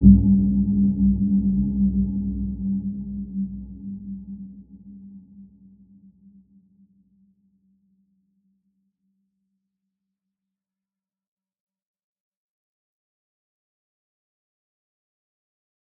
Dark-Soft-Impact-G3-mf.wav